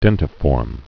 (dĕntə-fôrm)